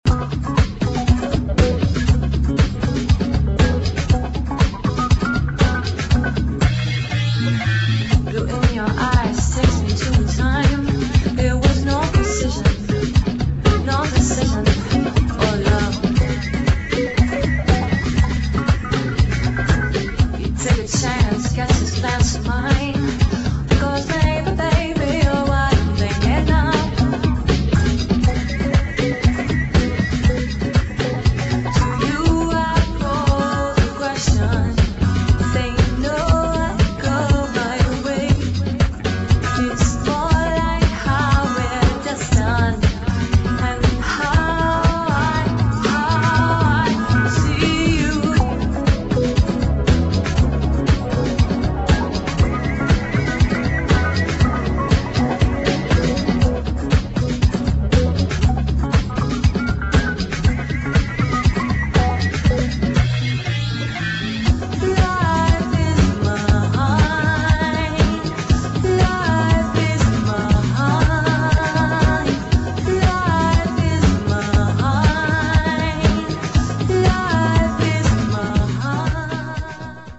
[ BREAKBEAT / HOUSE / FUTURE JAZZ ]